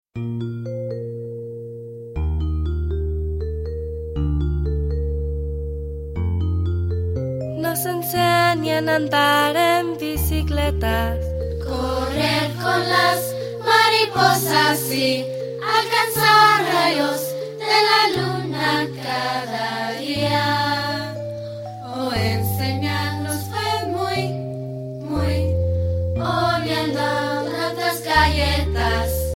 ▪ The full-length music track with vocals.
Demo MP3